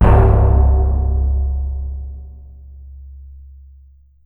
Synth Impact 05.wav